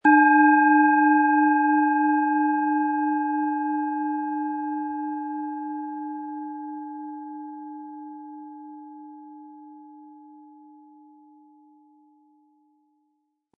Von erfahrenen Fachkräften in einem indischen Dorf wurde diese Neptun Klangschale von Hand hergestellt.
Der gratis Klöppel lässt die Schale wohltuend erklingen.
MaterialBronze